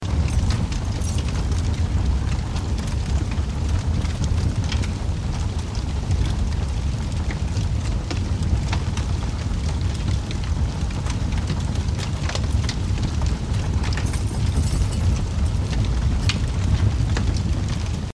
sfx_fire.mp3